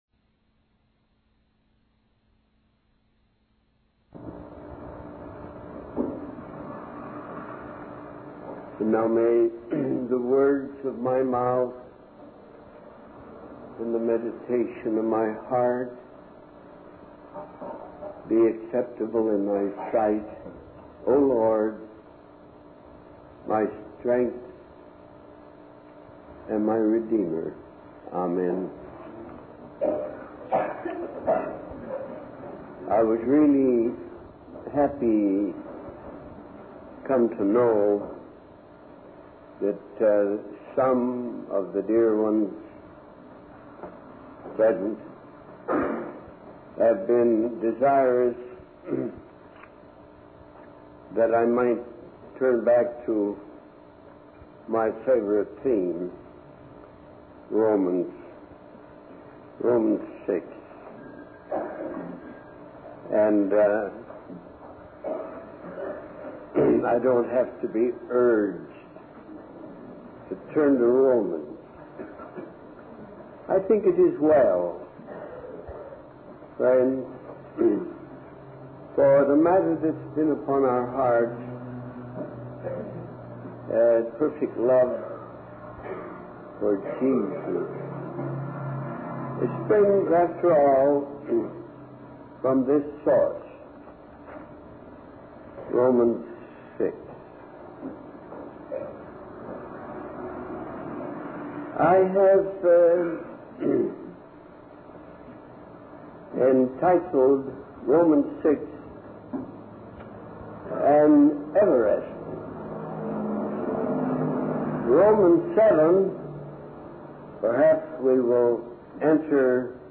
In this sermon, the speaker recounts a story about a general who experiences a transformative moment.